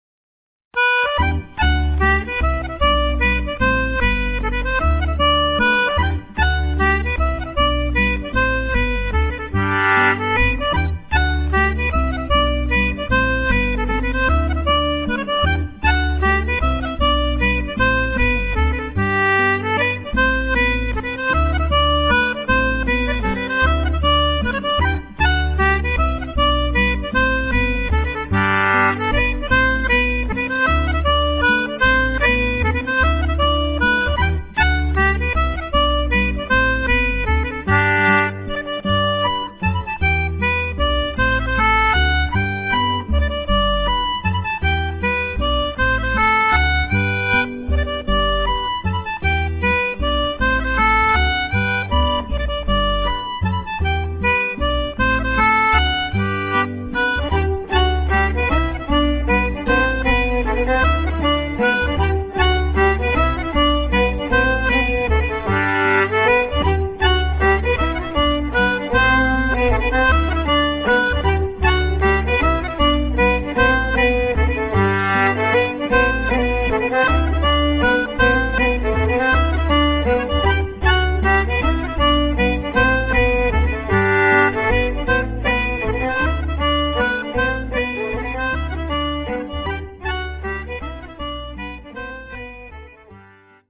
redowa waltz, slides